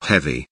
heavy kelimesinin anlamı, resimli anlatımı ve sesli okunuşu